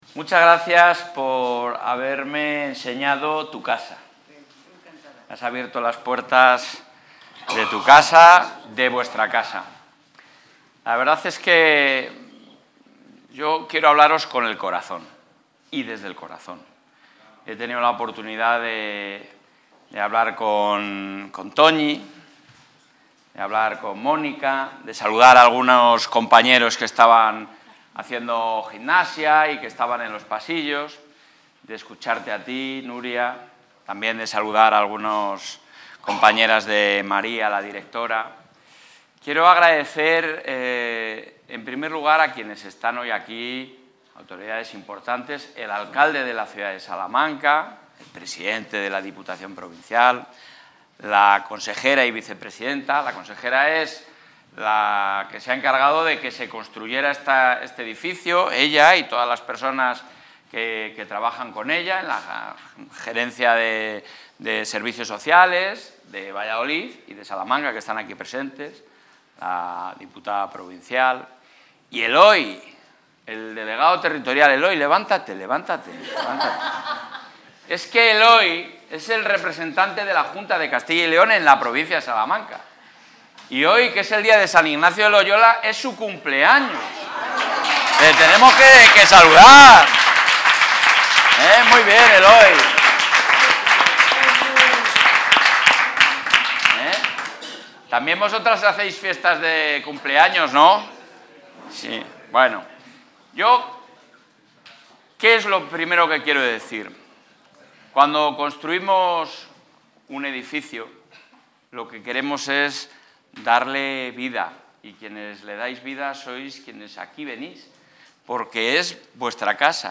Intervención del presidente de la Junta.
Durante su visita a la Nueva residencia de mayores San Juan de Sahagún en Salamanca, el presidente de la Junta de Castilla y León, Alfonso Fernández Mañueco, ha destacado que este centro es un fiel reflejo de la gran apuesta del Ejecutivo autonómico por el nuevo modelo residencial. Además, ha recordado que es la primera residencia pública completamente adaptada a este nuevo modelo de atención centrada en la persona, más cercana, respetuosa y humana.